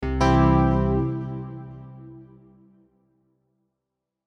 Victory SoundFX4.wav